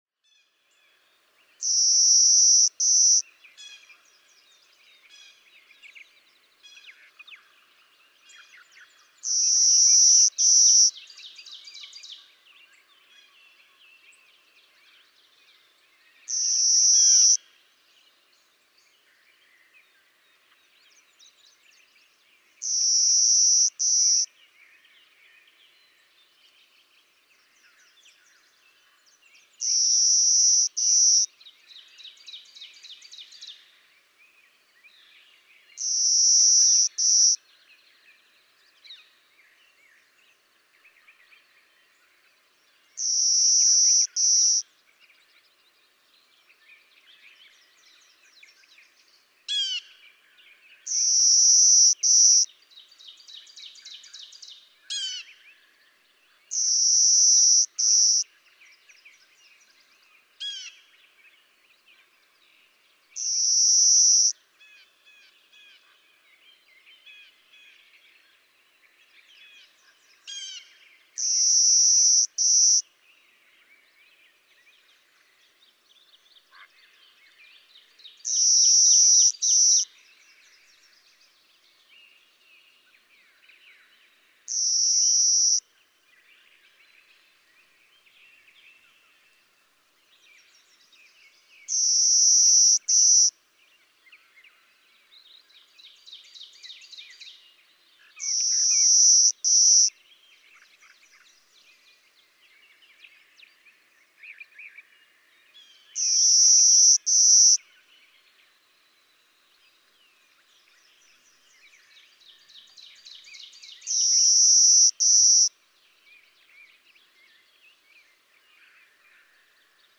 Clay-colored sparrow
A daytime singer, with the same buzzy, insectlike song repeated over and over.
Walsh Road, Au Sable State Forest, Lewiston, Michigan.
506_Clay-colored_Sparrow.mp3